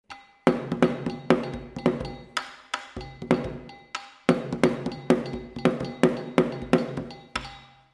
Kinka An Anlo-Ewe Secular Dance-Drumming
Gankogui (bitonal bell)
Atsimevu (lead drum)
Sogo (support drum)
Kidi (support drums)
KINKA DRUM MUSIC